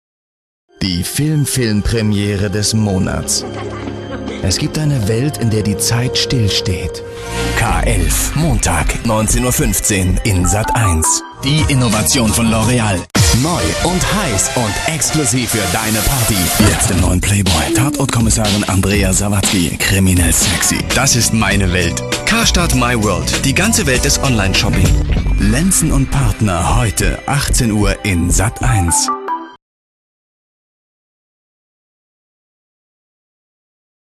Deutscher Sprecher und Schauspieler.
Sprechprobe: Werbung (Muttersprache):